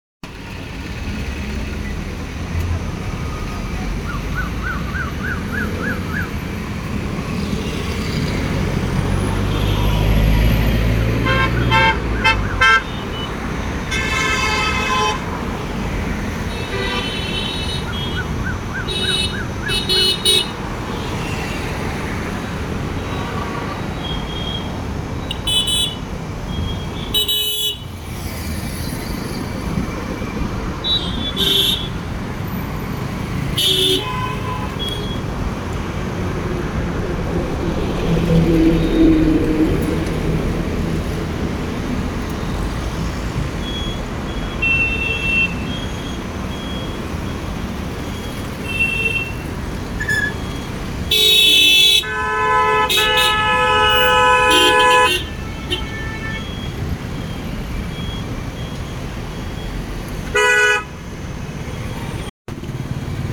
Download Traffic sound effect for free.
Traffic